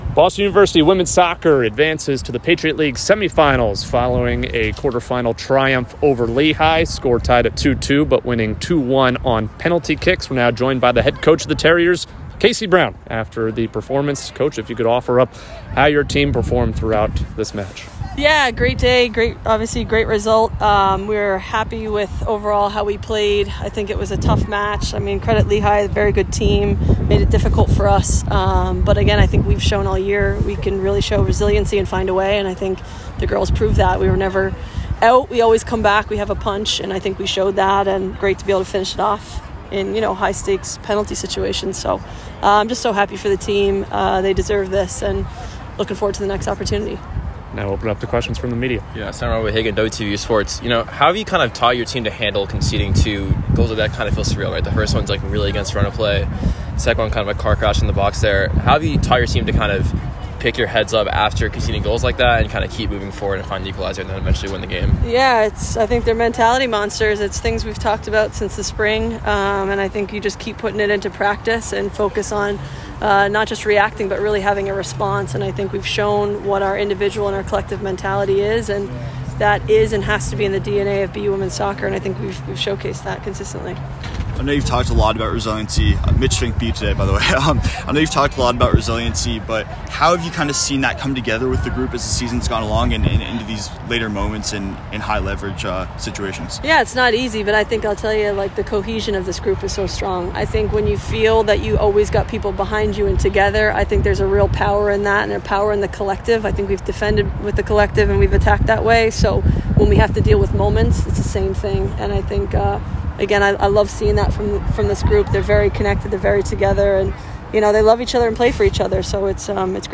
Women's Soccer / PL Quarterfinal Postgame Interviews (10-29-23)